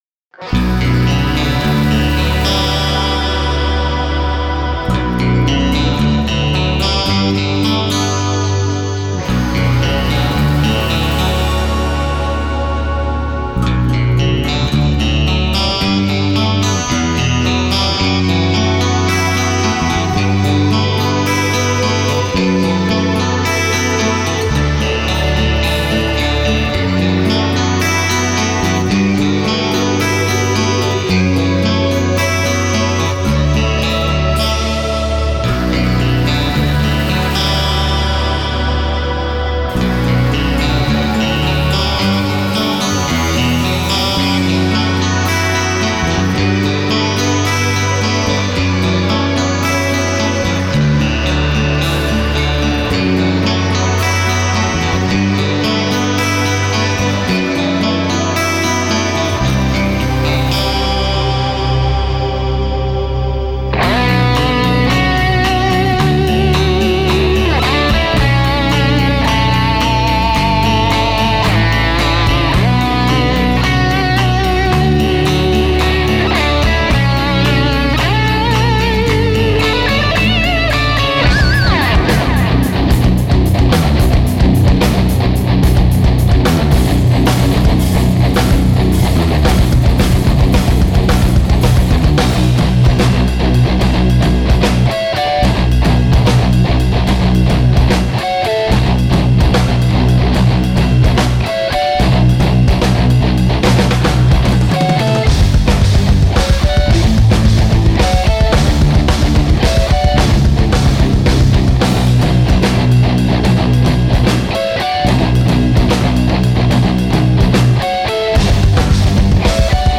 (heavy metal)